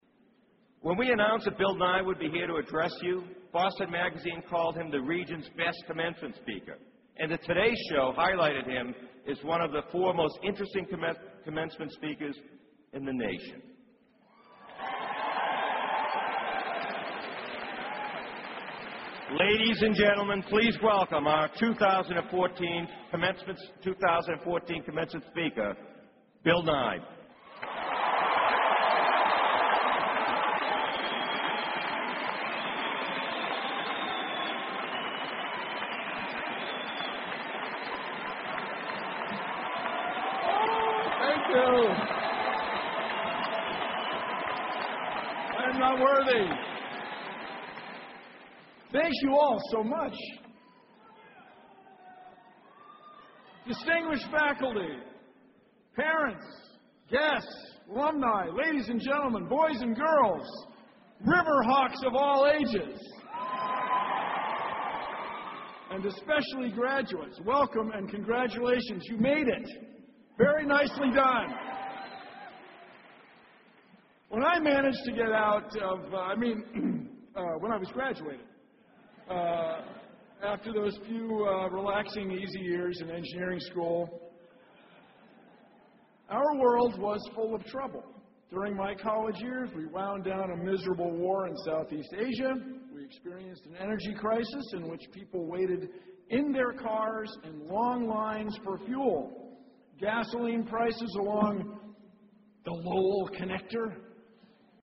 公众人物毕业演讲 第156期:比尔·奈马萨诸塞大学2014(3) 听力文件下载—在线英语听力室